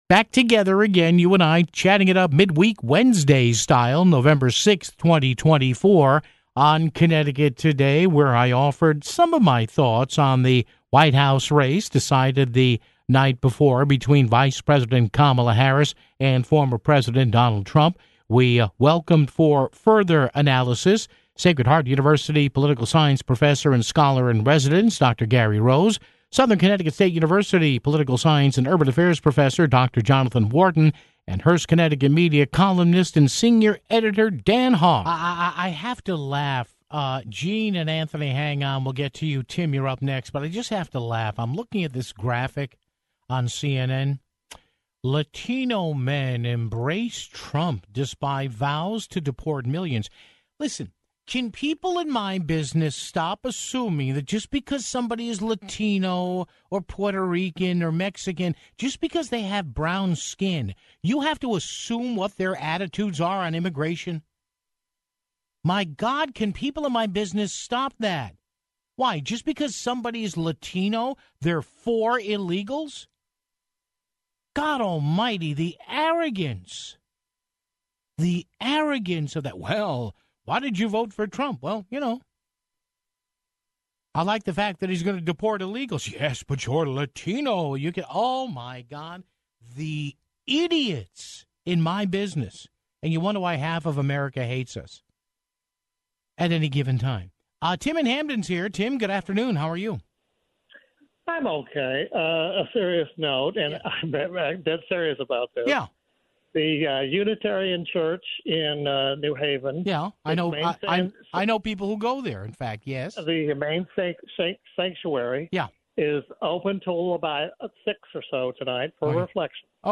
Post-Election Reaction & Analysis